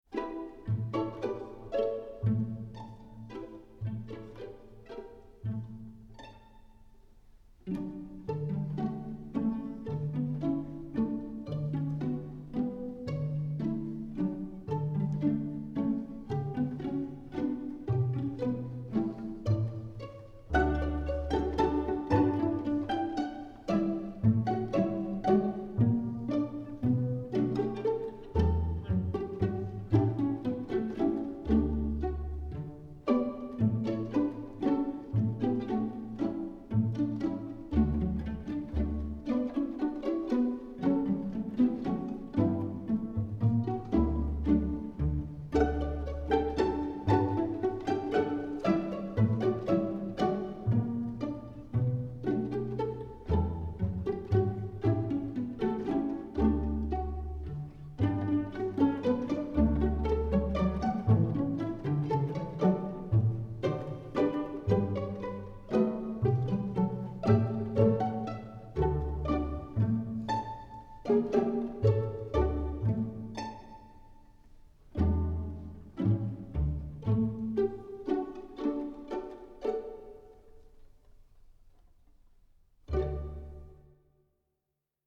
Composer: Spanish Folk Song
Voicing: String Orchestra